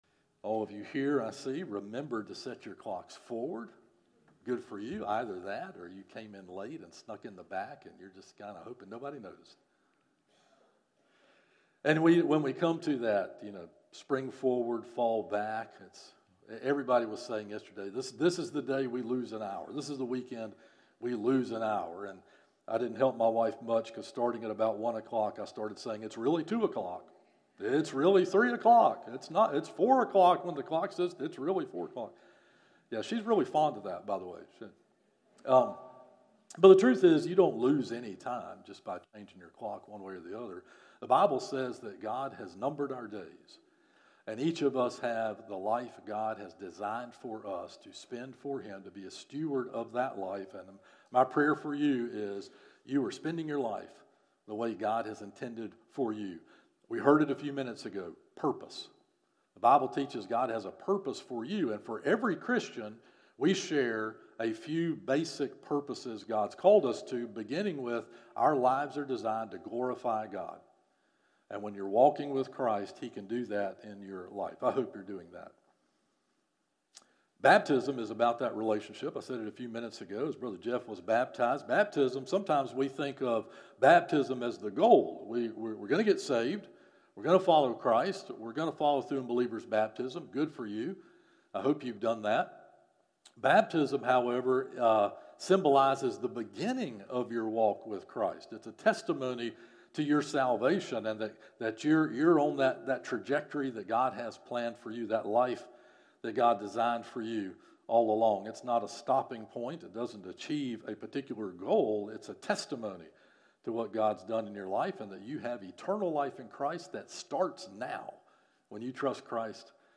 From Series: "Morning Worship - 11am"